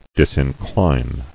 [dis·in·cline]